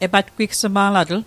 Pronunciation Guide: e·bat·kwik·sê·maa·la·dêl